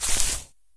default_grass_footstep.3.ogg